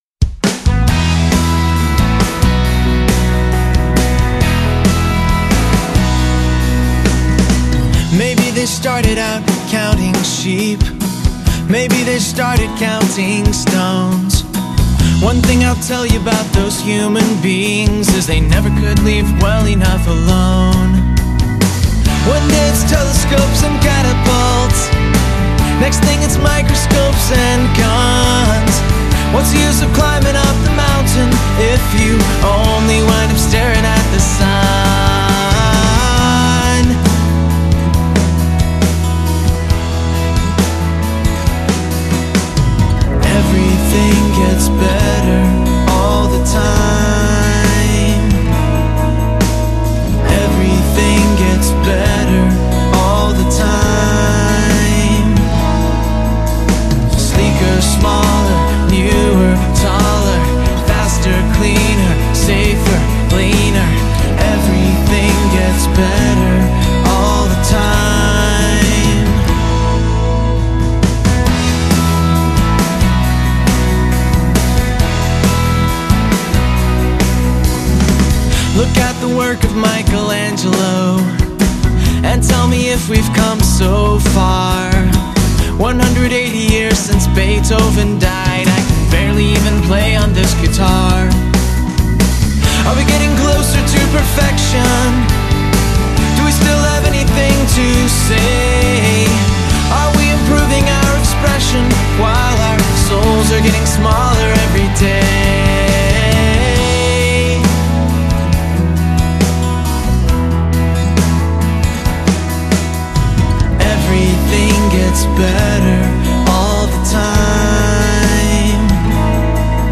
lead vocals, background vocals, acoustic guitars, harmonica
drums, percussion
bass guitar
electric guitars
piano, organ, rhodes, keys
accordian, mandolin, high strung guitar